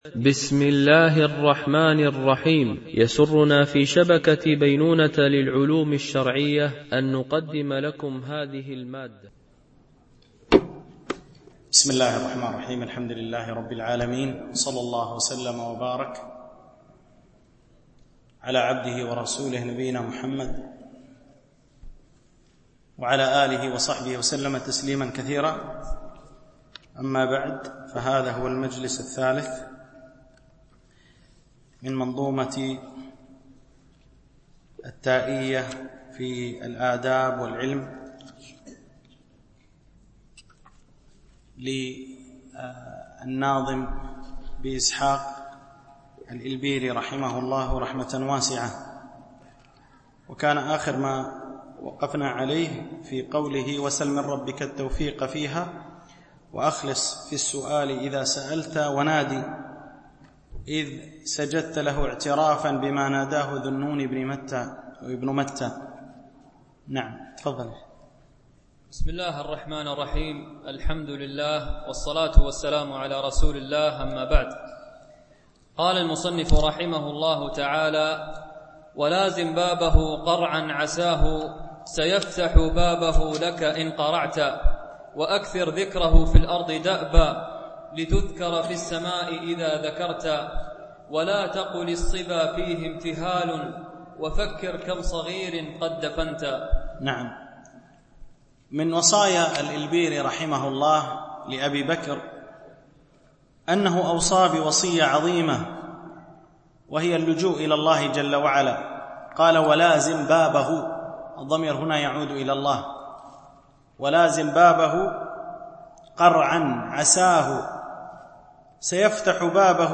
شرح تائية الالبيري - الدرس 3